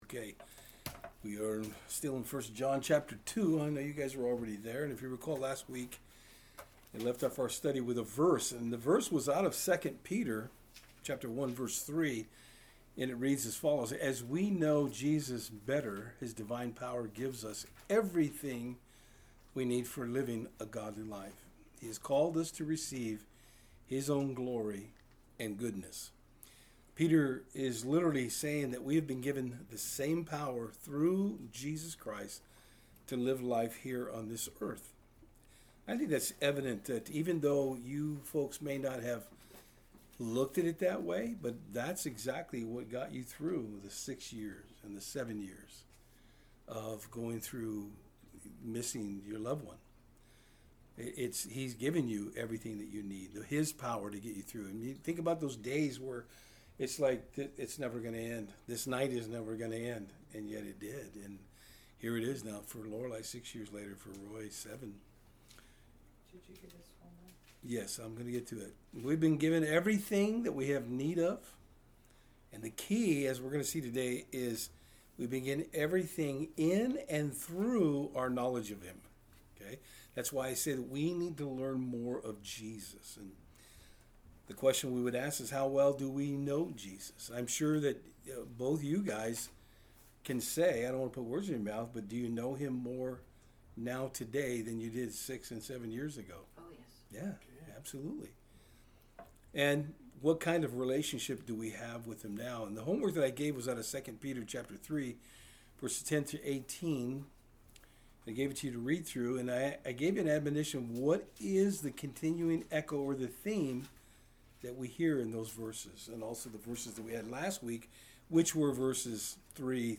1 John 2:7-11 Service Type: Thursday Eveing Studies The Apostle John takes us trough the steps of what it truly means to love like Jesus did.